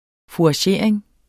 Udtale [ fuɑˈɕeˀɐ̯eŋ ]